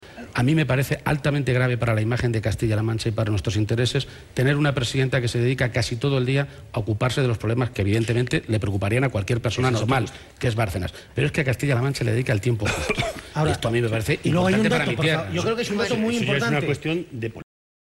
García-Page se pronunciaba de esta manera en una entrevista en el programa “Al Rojo Vivo”, de La Sexta, en la que insistía en que Cospedal reconoció la recepción de esos 200.000 euros.
Cortes de audio de la rueda de prensa
Page-entrevista_al_rojo_vivo_2.mp3